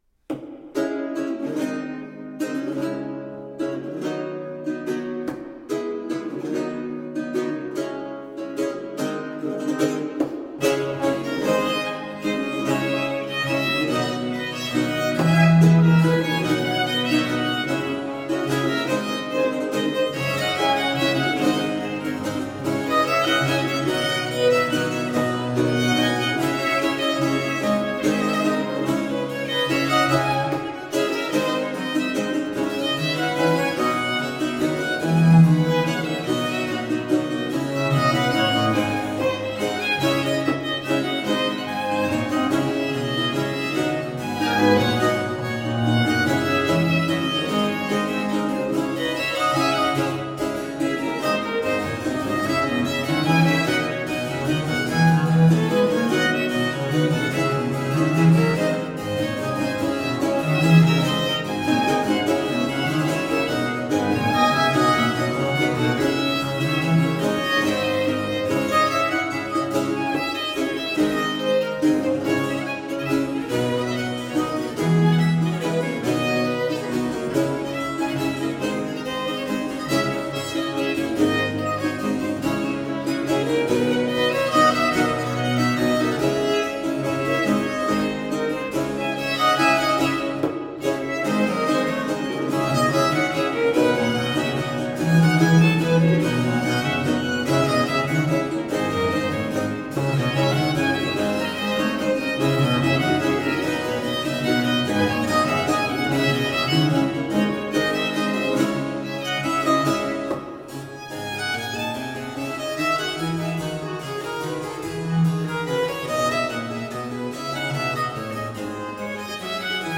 Rare and extraordinary music of the baroque.
using period instruments